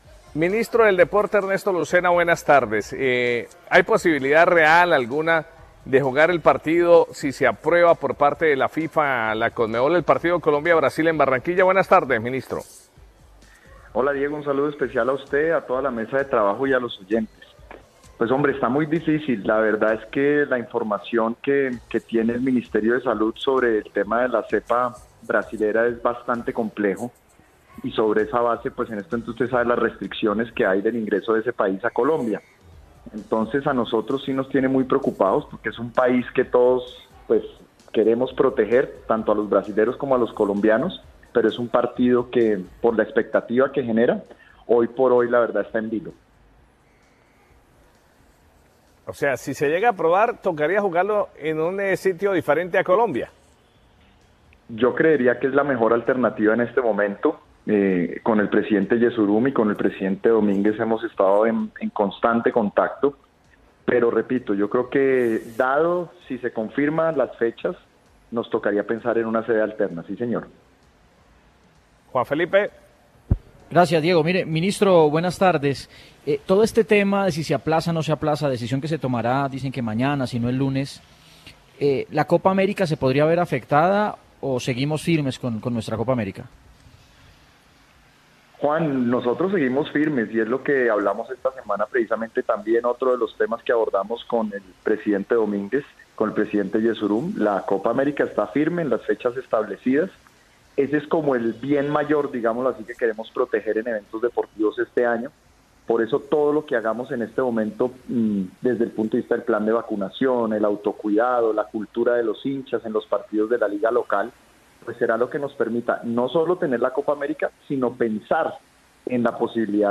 Ernesto Lucena en entrevista con el VBAR de Caracol Radio